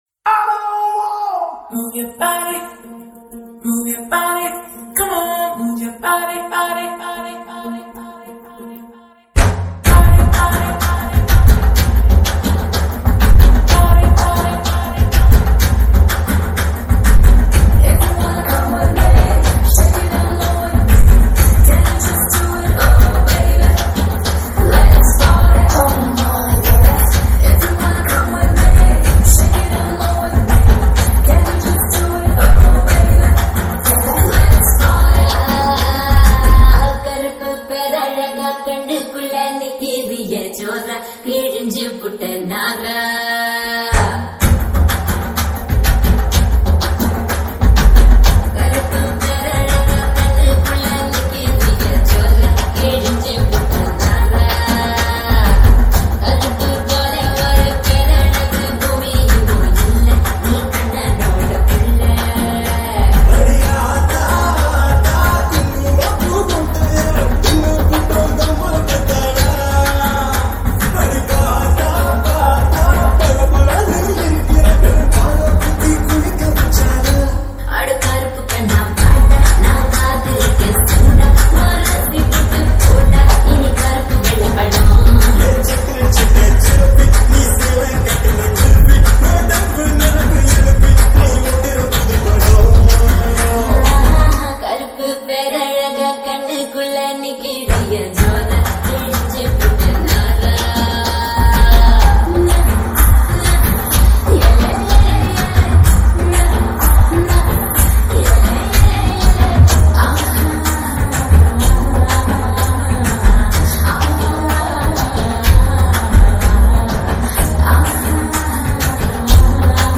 Tamil 8D Songs